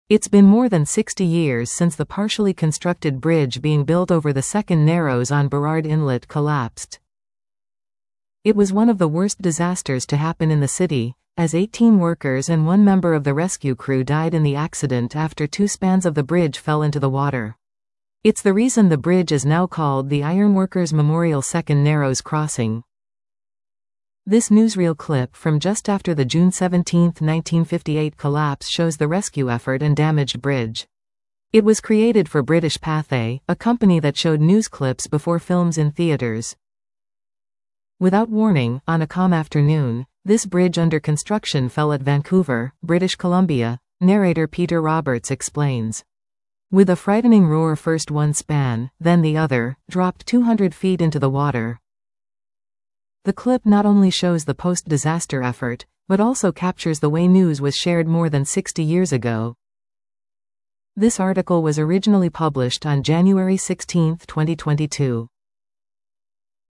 Historic newsreel shows aftermath of Vancouver bridge collapse - Vancouver Is Awesome
The clip would have been shown in movie theatres.